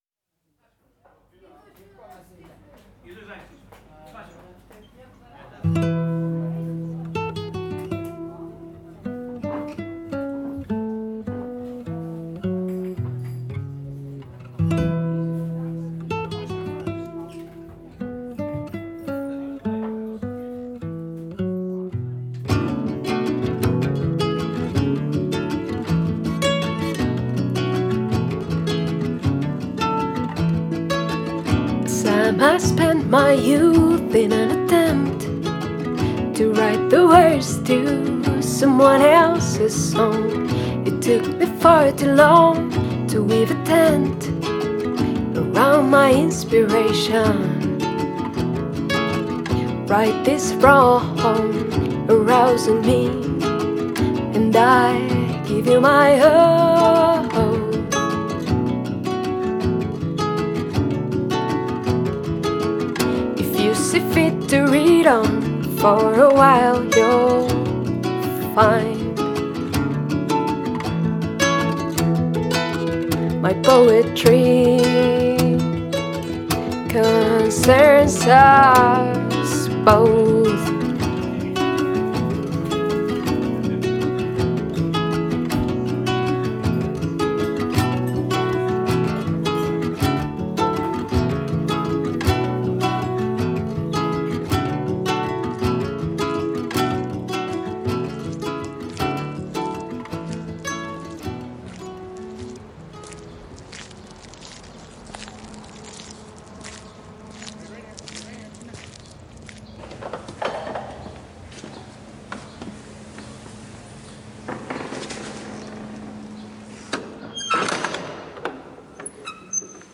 Voz y guitarra acústica
Guitarra acústica
Grabado en Cholula, México, 2022.